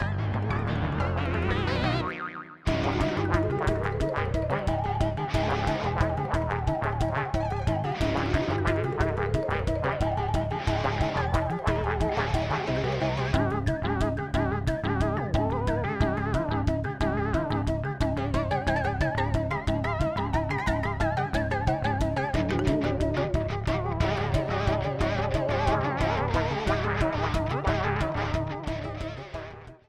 Ripped from game